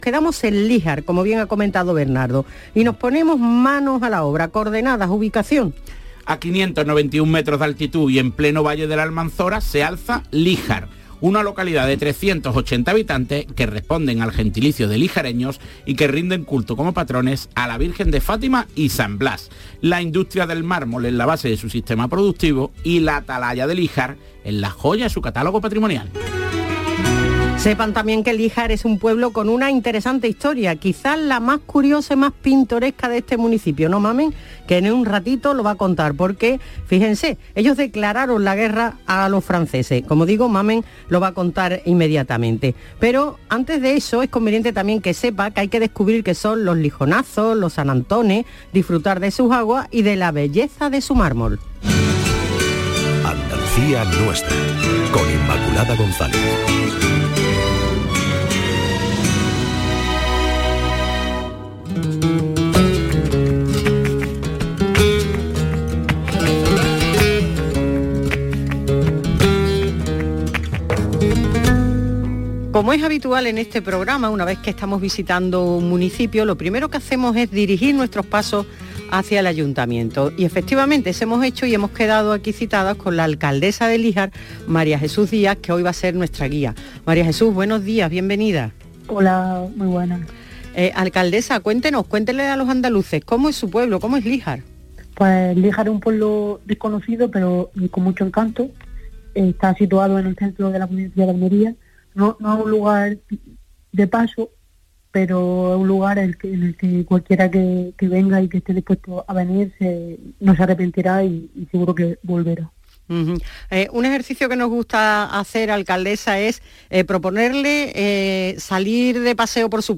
Reportaje de Andalucía Nuestra de Canal Sur Radio sobre Líjar - Interior de Almeria - Valle del Almanzora
Corte del programa dedicado a Líjar: